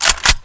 g3sg1_boltpull.wav